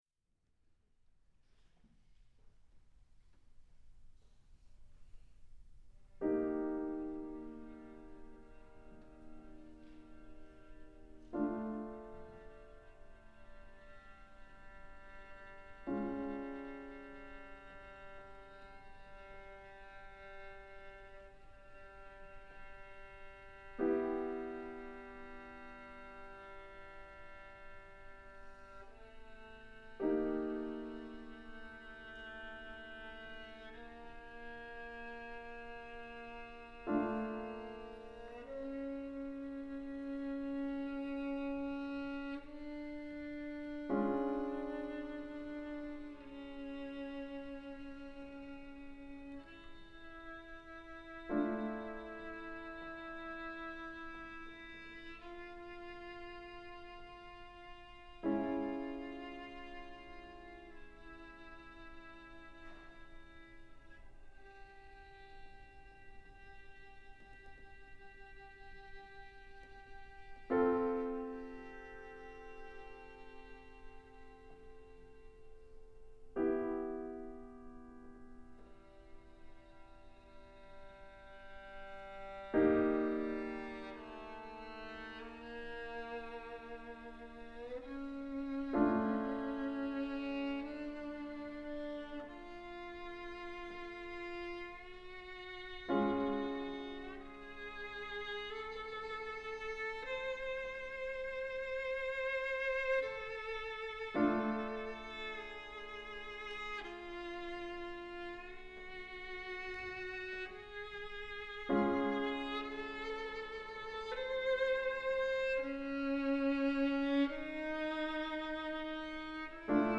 Premiere of elegy , for violin and piano